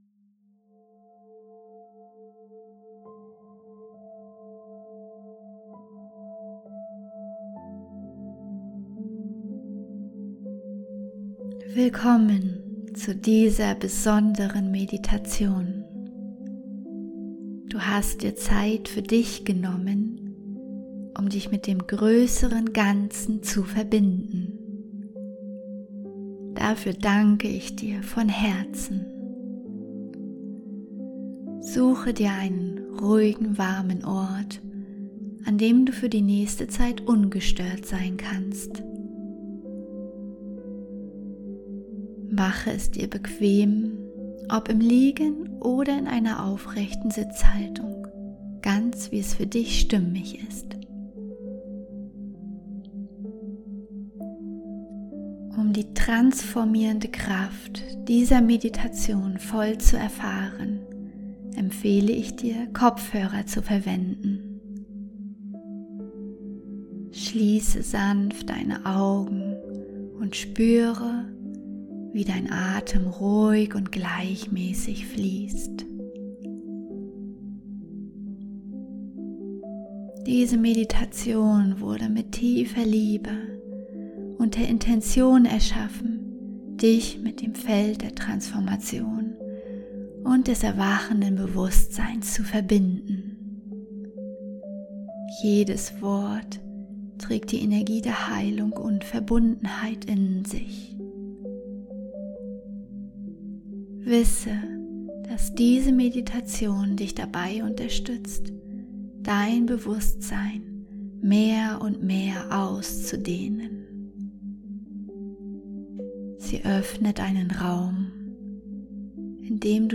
Meditation: Bewusstseinsfelder verbinden
Wichtige Hinweise: Nutze unbedingt kabelgebundene Kopfhörer , damit die binauralen Beats ihre volle Wirkung entfalten können.
Stelle sicher, dass du ungestört bist und schalte dein Handy in den Flugmodus Du kannst während der Meditation gerne einschlafen – dein Unterbewusstsein nimmt die Informationen trotzdem auf Wiederhole die Meditation regelmäßig für die beste Wirkung Der summende Ton im Hintergrund ist der Binaurale Ton, der dein Gehirn in den Theta-Zustand bringt Die Audio beinhaltet multiple Informationsströme, die dein Unterbewusstsein aufnehmen kann Meditation herunterladen Für den Fall, dass du über den obigen Button die Meditation nicht herunterladen kannst, verwende in der Meditationsanzeige die drei Punkte ganz rechts und wähle „Herunterladen“.